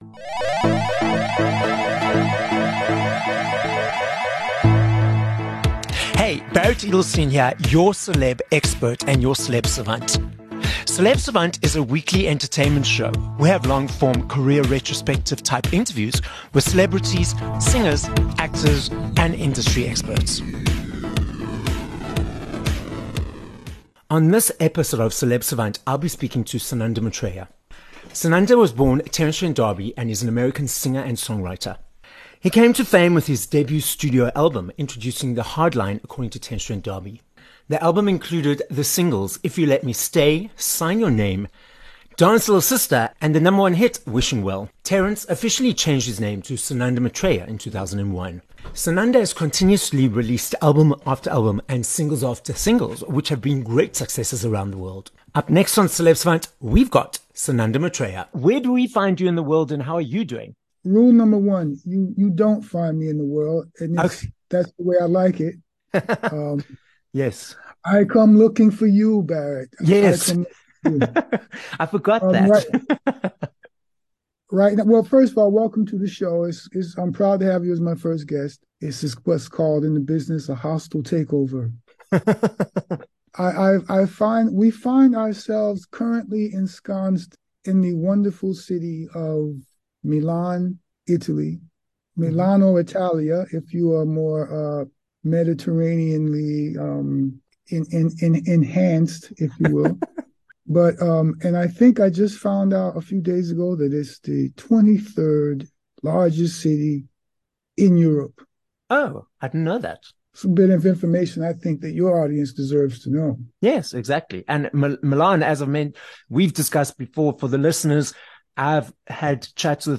22 Mar Interview with Sananda Maitreya